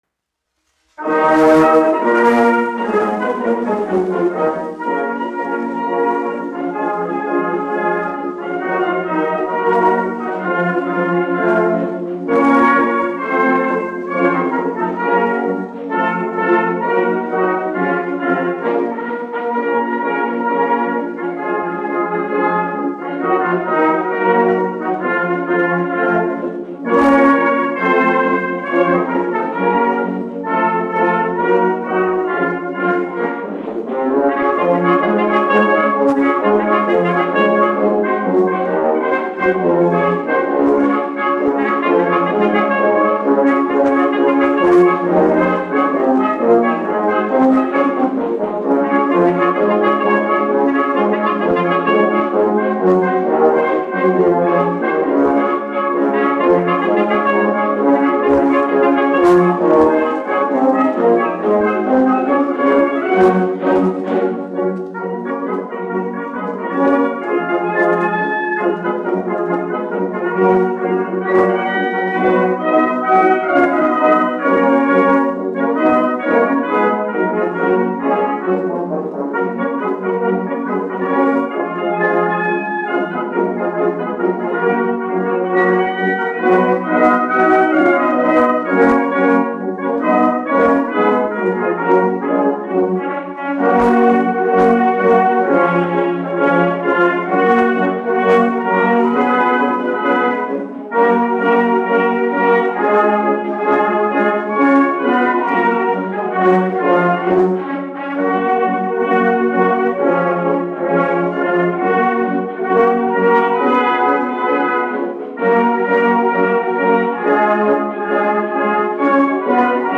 1 skpl. : analogs, 78 apgr/min, mono ; 25 cm
Marši
Pūtēju orķestra mūzika